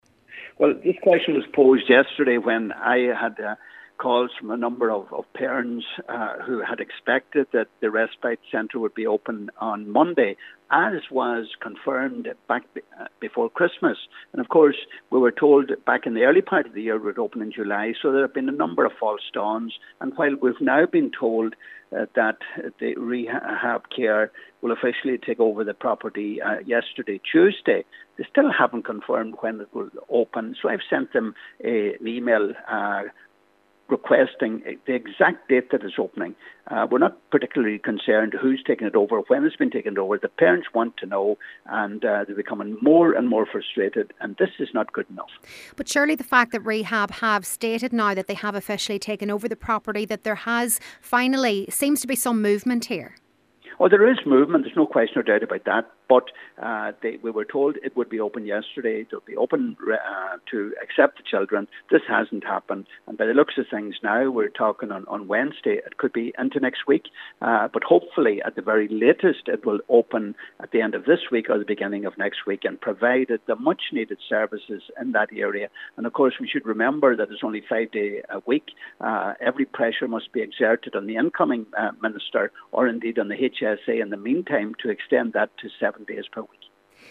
But Deputy Pat the Cope Gallagher believes that families deserve more clarity: